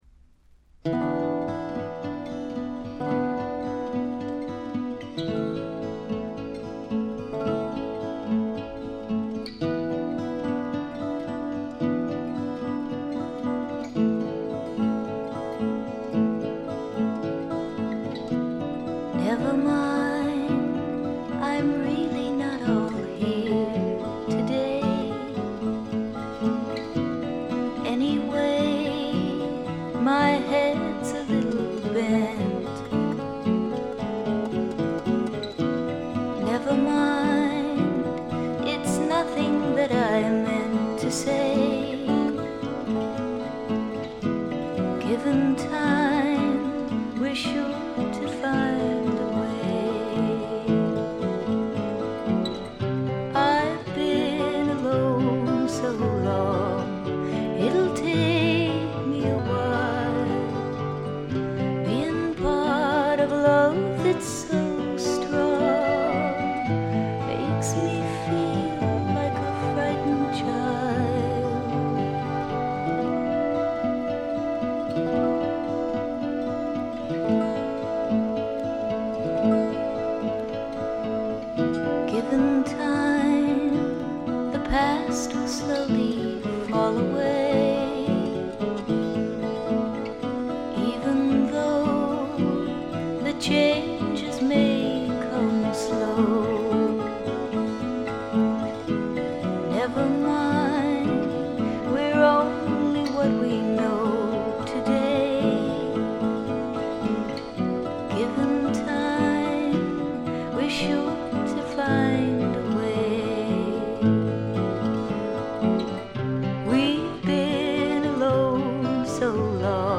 ほとんどノイズ感無し。
ルックスよりも少しロリ寄りの声で、ちょっとけだるくてダークでたまらない魅力をかもしだしています。
試聴曲は現品からの取り込み音源です。
Recorded at T.T.G. Studios, Hollywood